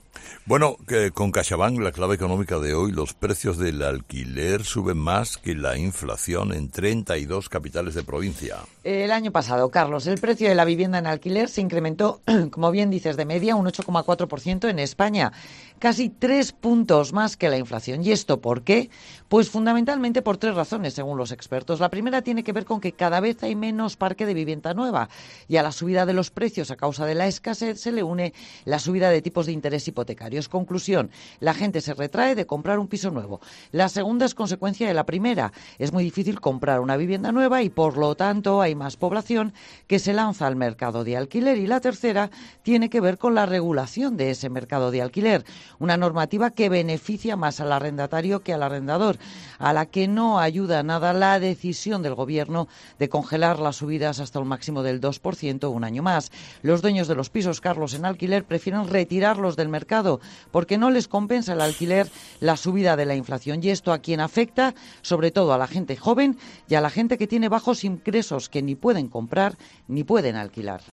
La periodista económica Pilar García de la Granja ha analizado en 'Herrera en COPE' la situación de los precios del alquiler en España, que suben más que la inflación en 32 capitales de provincia.